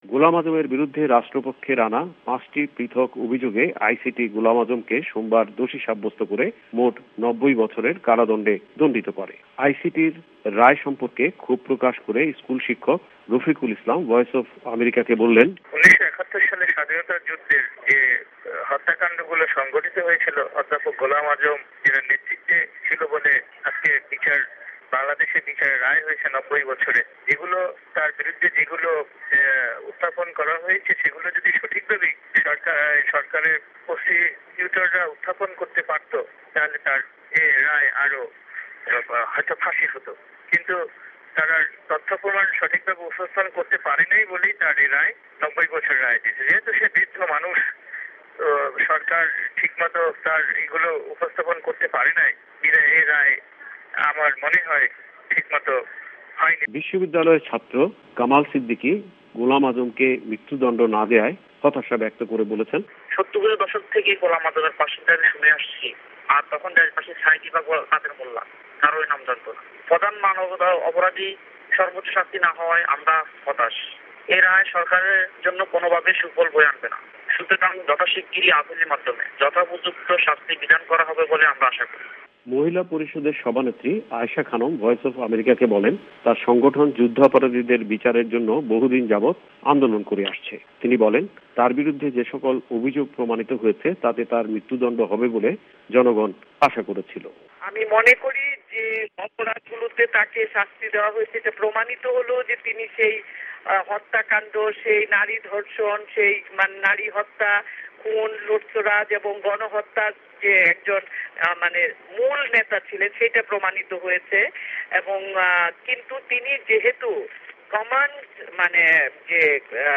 বাংলাদেশের যুদ্ধ অপরাধ ট্রাইব্যুনাল, জামায়াতে ইসলামীর সাবেক আমির গোলাম আযমের বিরুদ্ধে আনা ১৯৭১ সালে মুক্তিযুদ্ধকালে মানবতাবিরোধী অপরাধের অভিযোগে যে ৯০ বছরের কারাদণ্ডাদেশ দিয়েছেন সে বিষয়ে মিশ্র প্রতিক্রিয়া ব্যাক্ত করেছেন দেশের বিভিন্ন স্তরের মানুষ। এ সম্পর্কে ঢাকা থেকে রিপোর্ট...